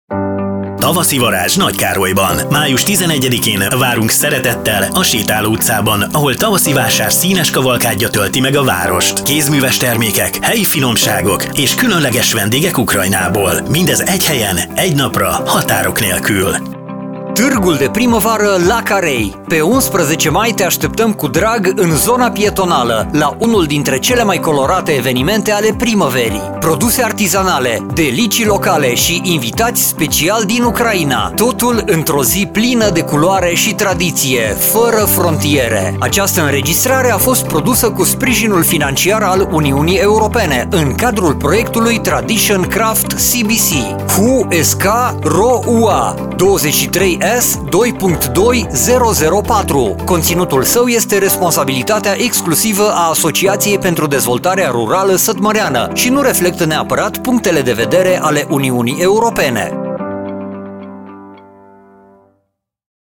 Radio spot - Târg de Primăvară - Tavaszi Vásár